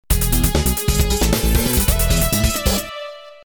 マルチバンドのコンプレッサー/エクスパンダーです。
2.0 Review の「Comp]」で使ったネタに、ベースとシンセリードを重ねて試してみました。
波形を見るだけでも、音が太ったのがはっきりわかりまが、
実際に音を聞くと、マルチバンドの効果絶大です。
低音域ともに、そんなに貧弱にはなっていません。